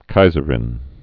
(kīzər-ĭn)